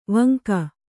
♪ vanka